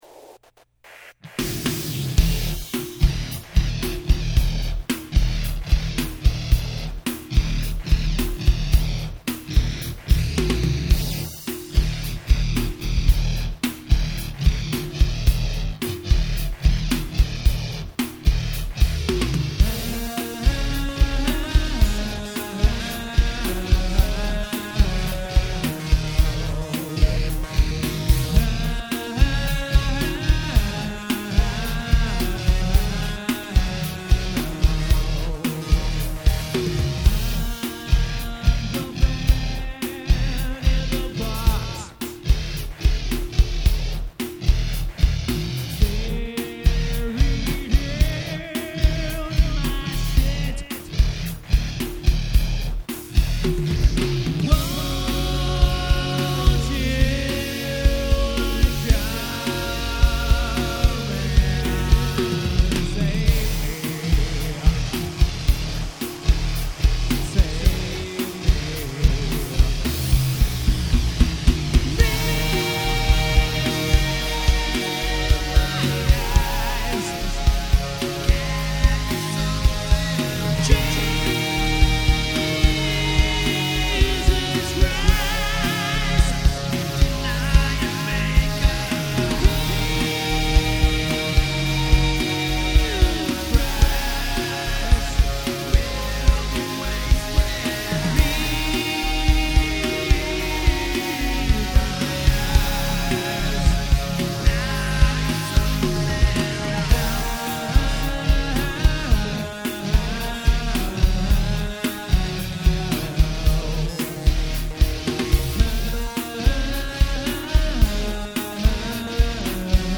is an electronic-rock duo whose debut album
vocals, bass
drums, backing vocals